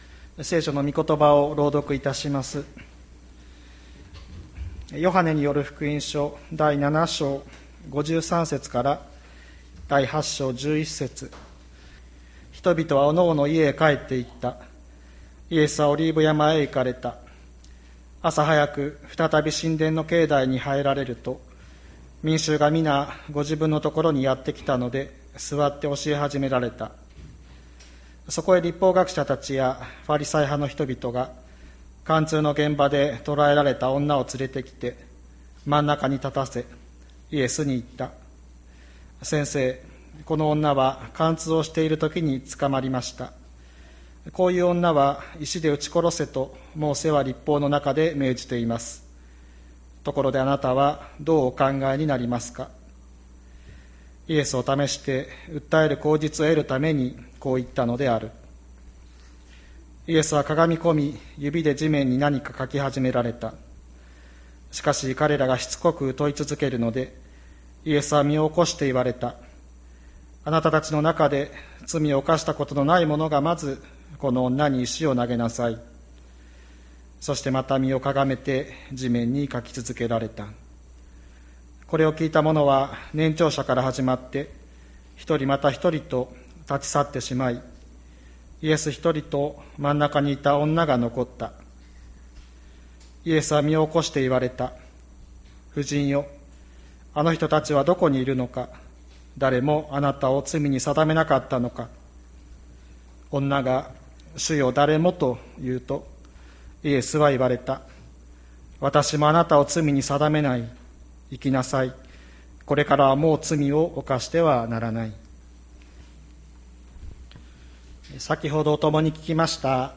2021年07月25日朝の礼拝「まことの裁き、まことの赦し」吹田市千里山のキリスト教会
千里山教会 2021年07月25日の礼拝メッセージ。